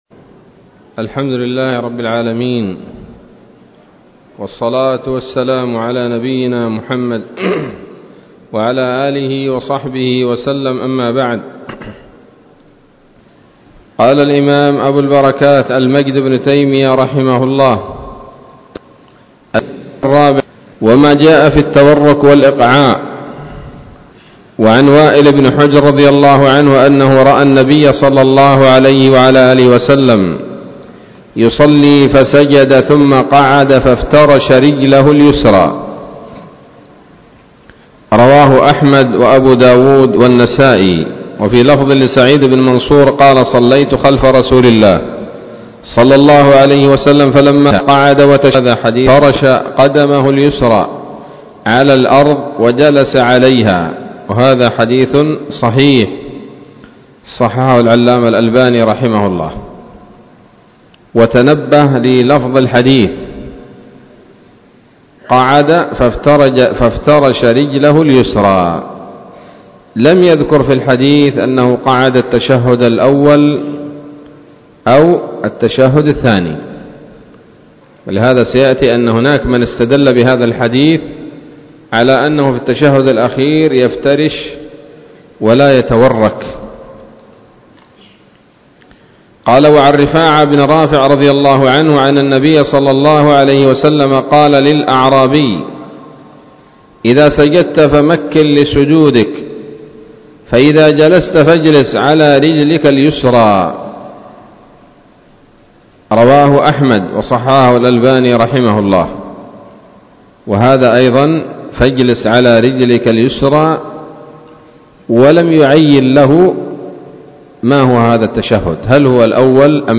الدرس الثاني والسبعون من أبواب صفة الصلاة من نيل الأوطار